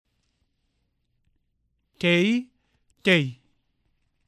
H.  Listen to the difference between äe and ei.  Notice also, that although the long and short forms look the same, the long form is two syllables, while the short form is just one.